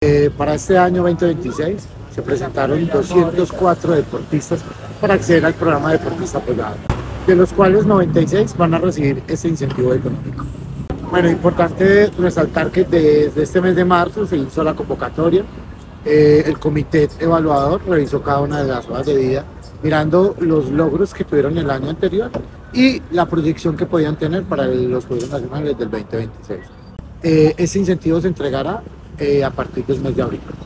Juan Pablo Echeverri, secretario (e) de Deporte, Recreación y Actividad Física de Caldas.
juan-pabli-ecehverri-secretario-de-deporte-recreacion-y-actividad-fisica-de-caldas-E.mp3